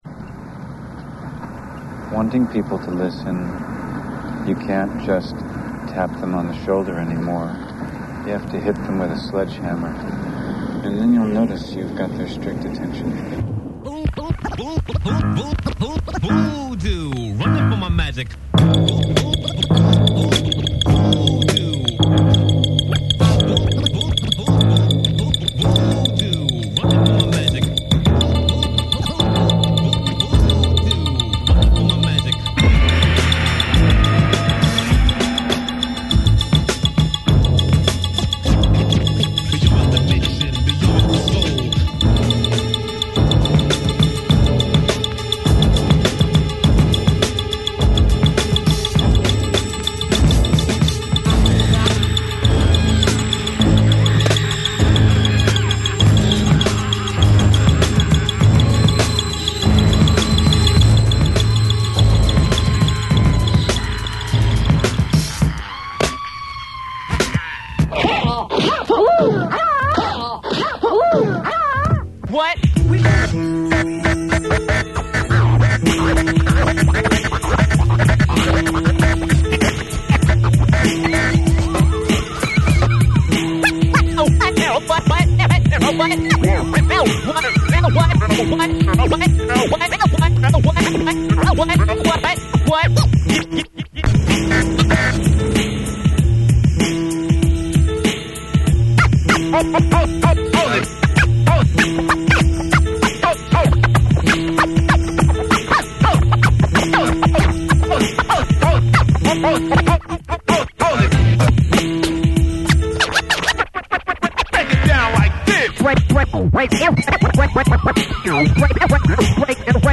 PS Dope Breakbeats + Scratch!!